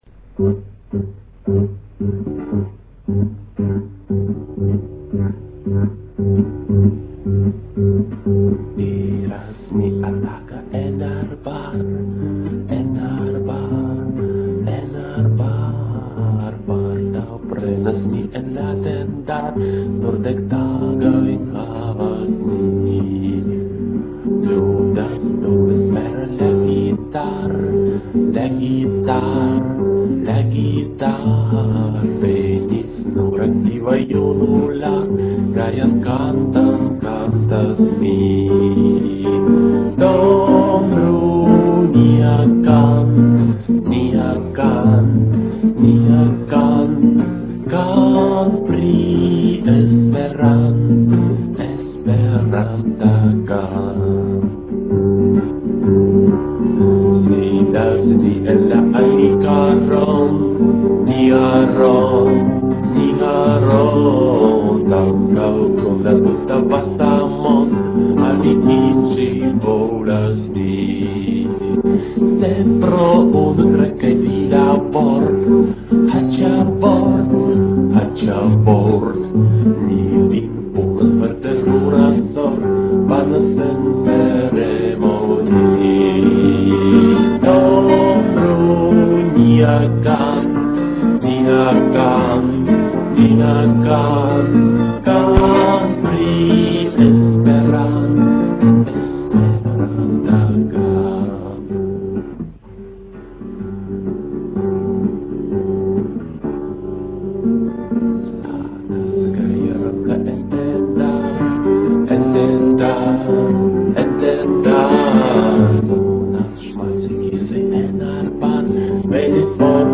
Rusperanta fokloro